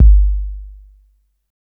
808-Kicks37.wav